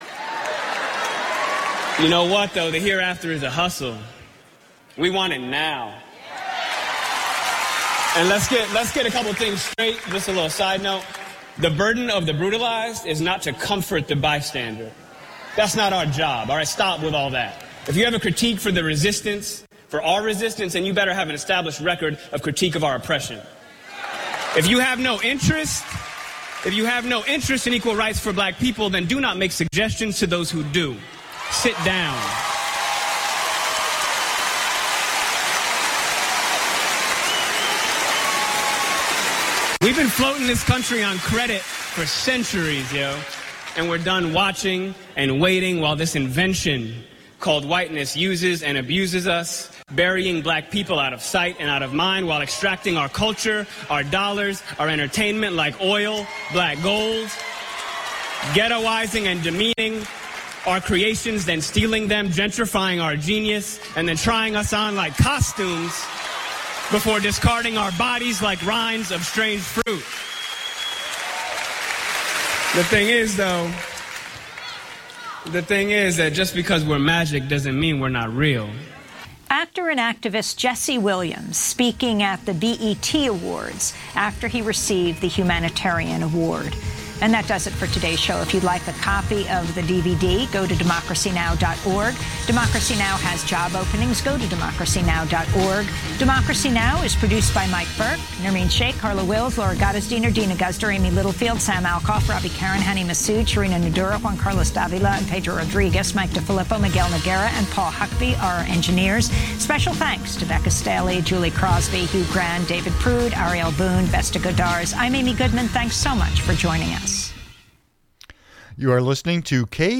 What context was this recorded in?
And they'll be taking calls, so you can join the conversation.